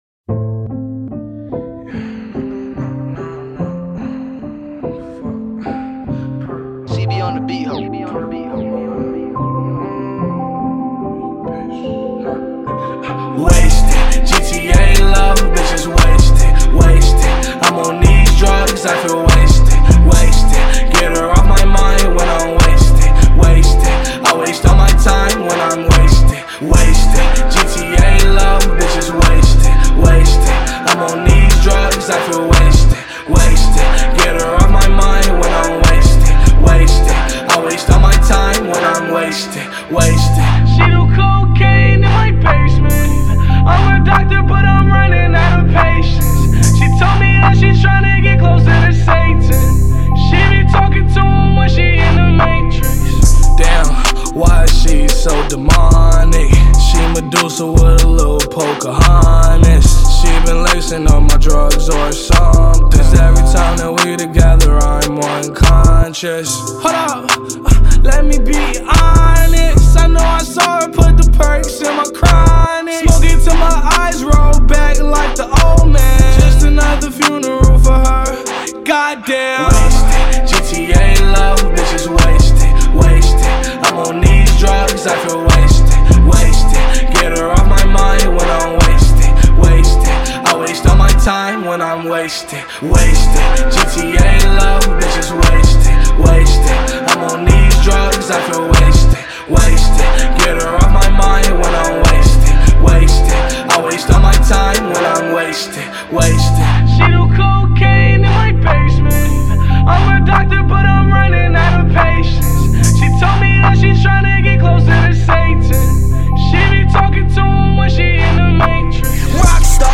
آهنگ رپ